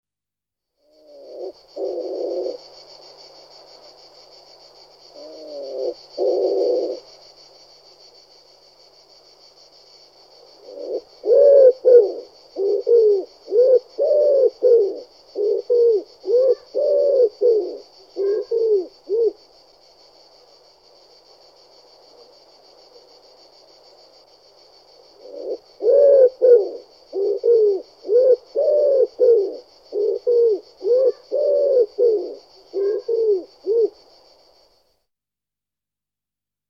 Звуки голубя
Тихие звуки голубя вяхиря в ночном лесу